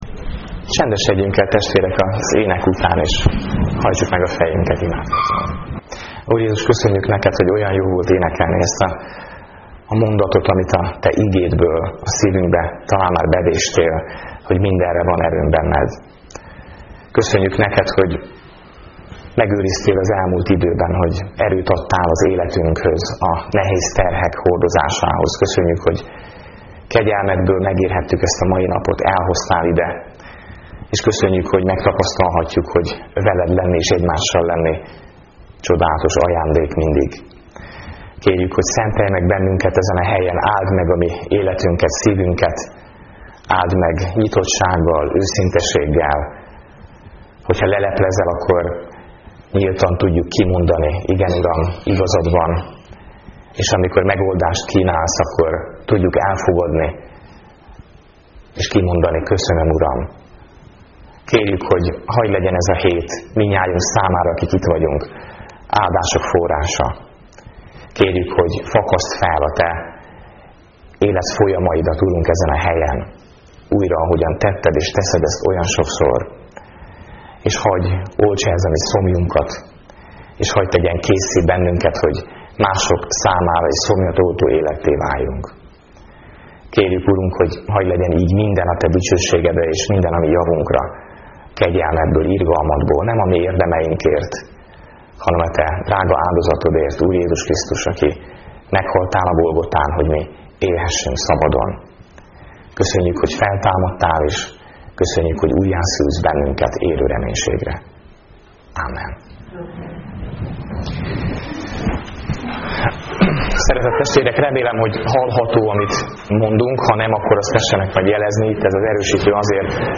Igehirdetések "Hegyi beszéd"